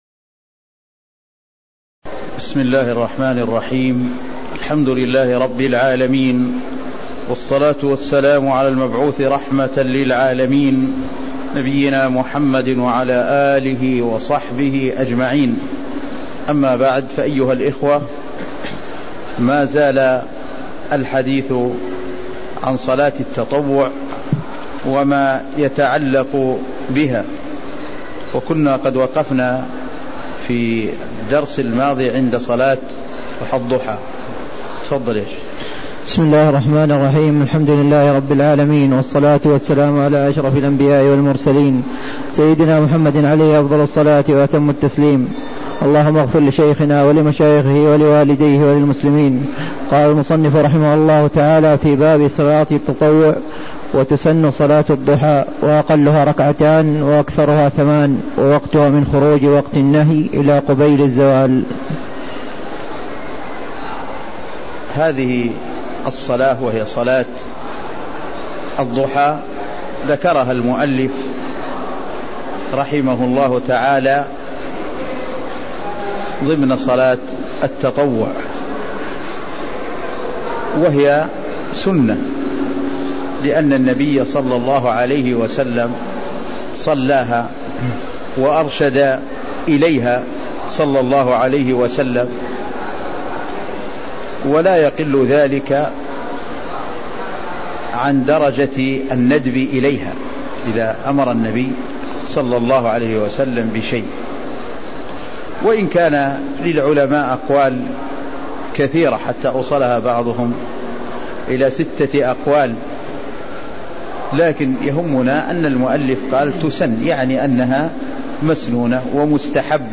تاريخ النشر ٢٥ محرم ١٤٣٦ هـ المكان: المسجد النبوي الشيخ